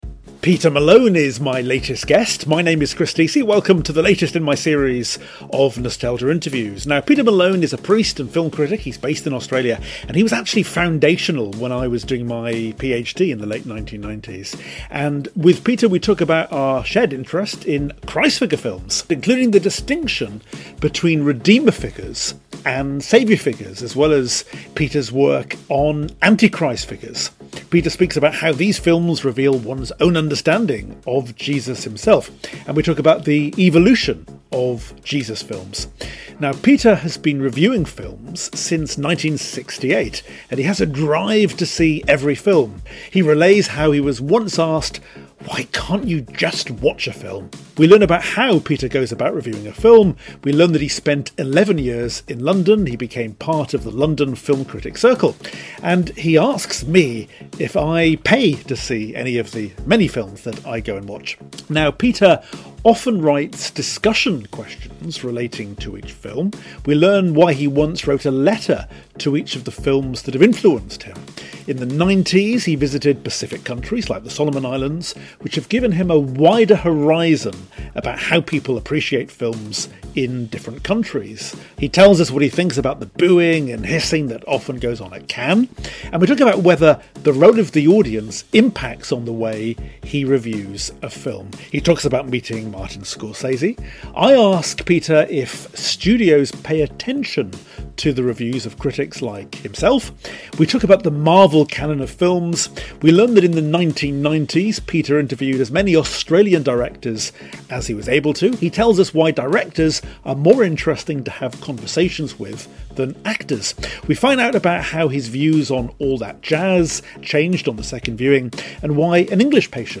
Nostalgia Interviews